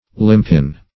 limpin - definition of limpin - synonyms, pronunciation, spelling from Free Dictionary Search Result for " limpin" : The Collaborative International Dictionary of English v.0.48: Limpin \Lim"pin\ (l[i^]m"p[i^]n), n. A limpet.